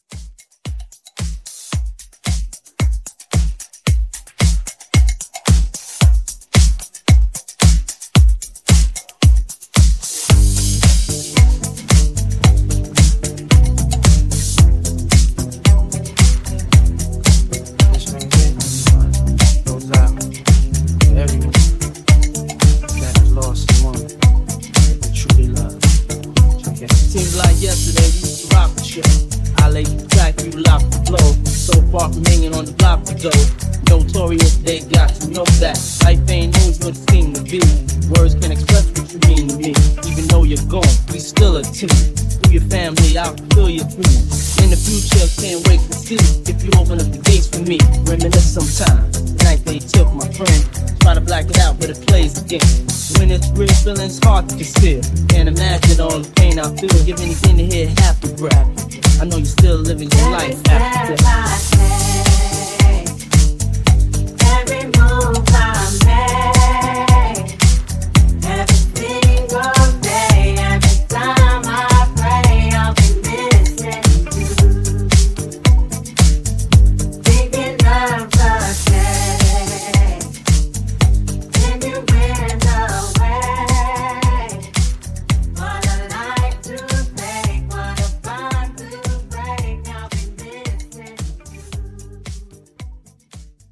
Genre: BOOTLEG
Dirty BPM: 127 Time